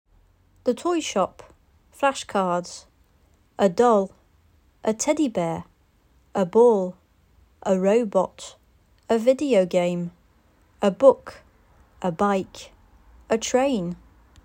Des fichiers audio avec une voix anglaise native accompagnent l'ensemble conçu prioritairement pour le Cycle 3, mais avec des adaptations possibles pour le Cycle 2.